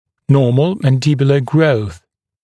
[‘nɔːml ˌmæn’dɪbjulə grəuθ][‘но:мл ˌмэн’дибйулэ гроус]нормальный рост нижней челюсти